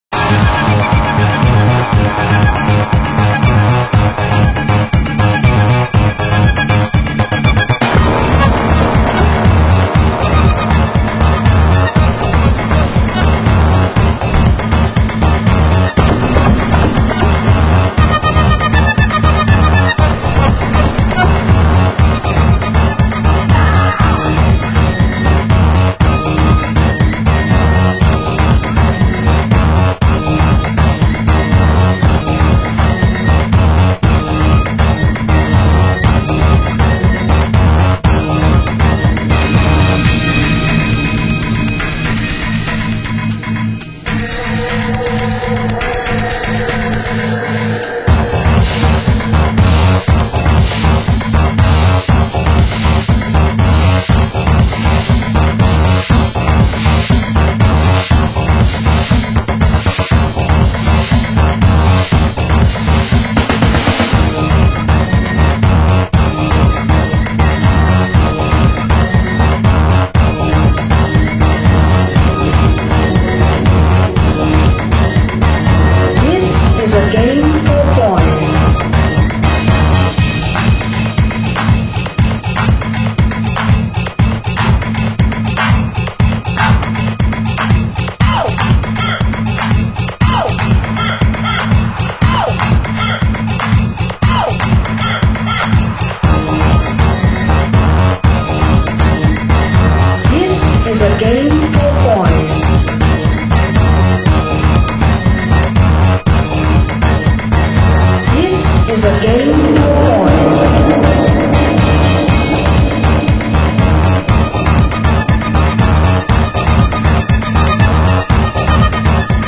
Genre: New Beat